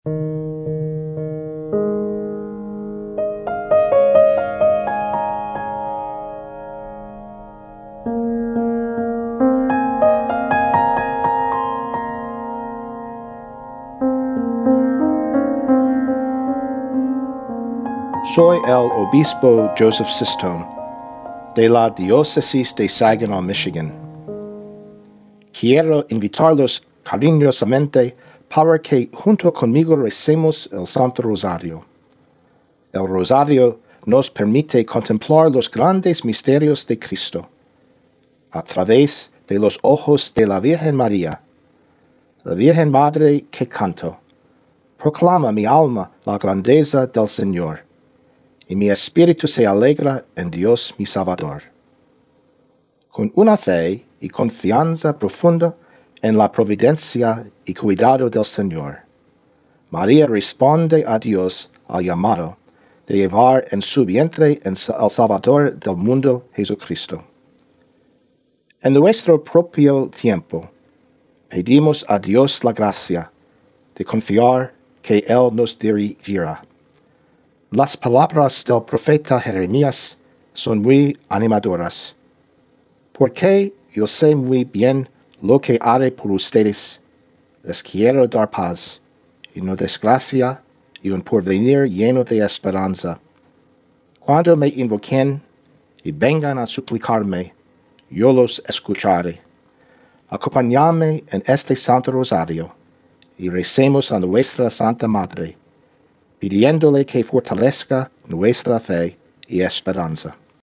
Introuduction final 117 with music.wav